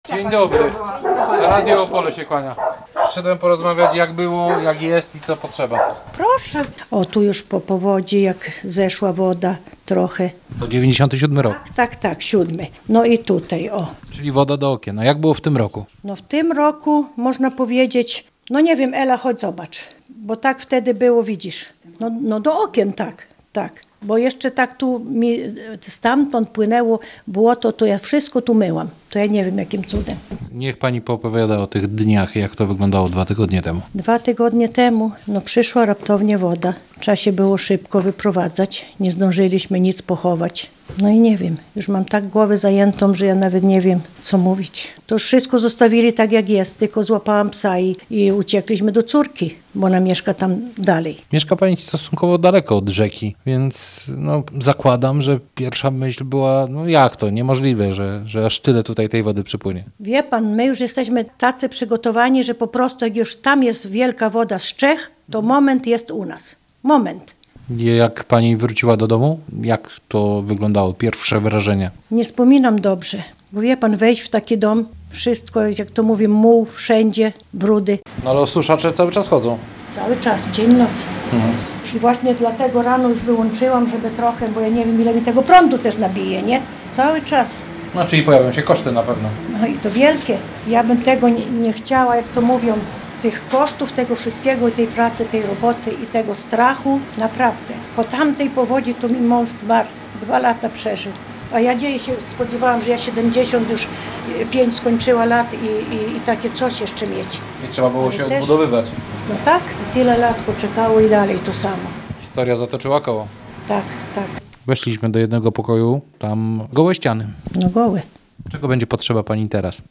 Rozmowa-z-mieszkanka-Boboluszek2.mp3